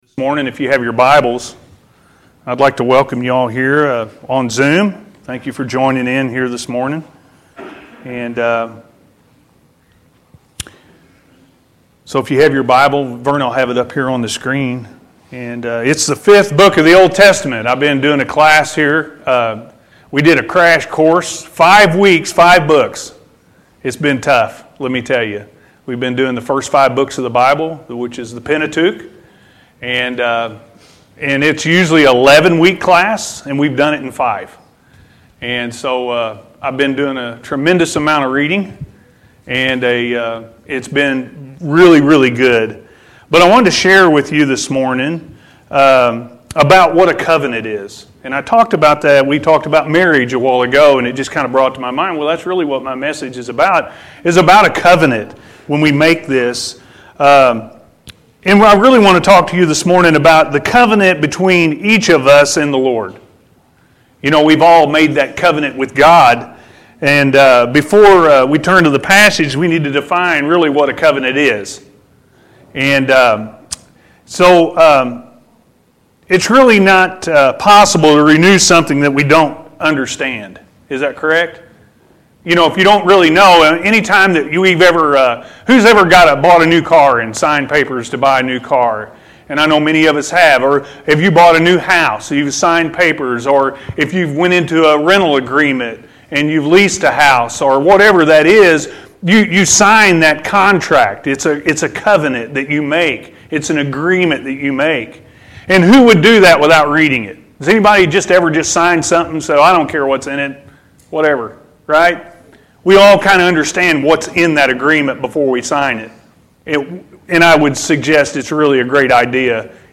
Our Covenant With God-A.M. Service